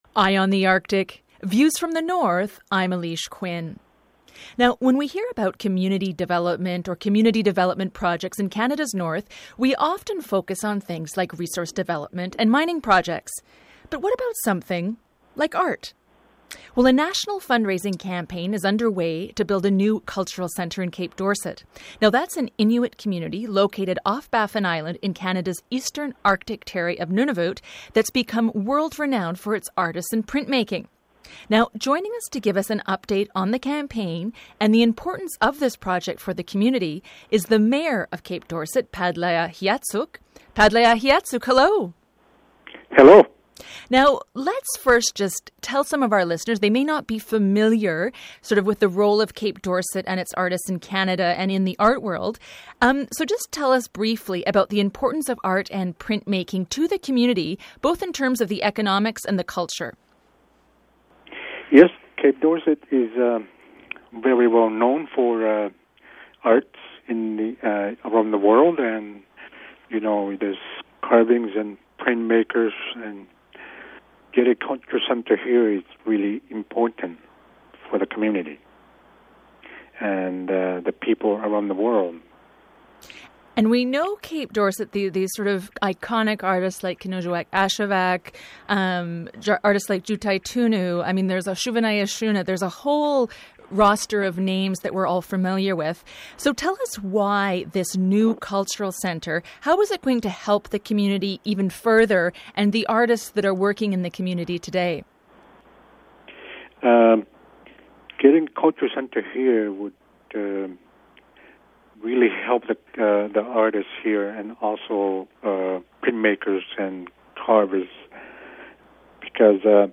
Feature Interview: Arctic hamlet drives cultural centre campaign
To find out more, Eye on the Arctic spoke to Cape Dorset mayor Palaya Qiatsuq for an update on the project and what it means to the community: